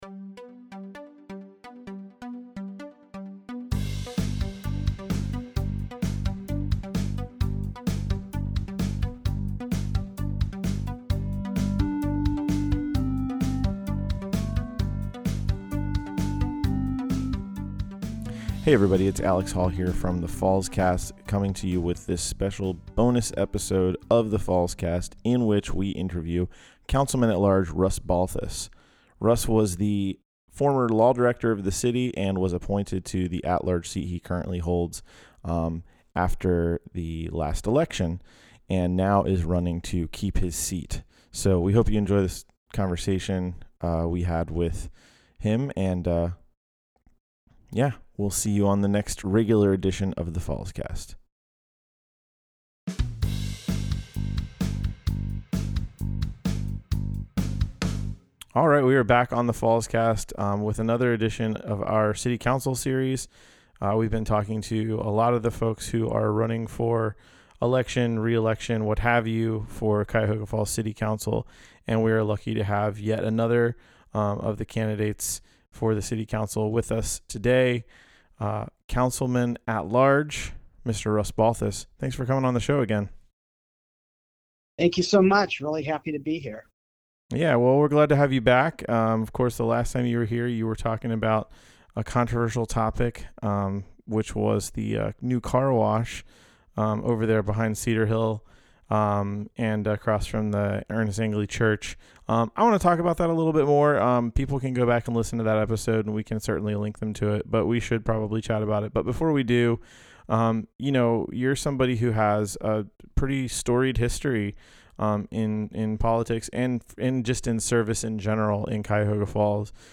Fallscast Bonus Candidate Interview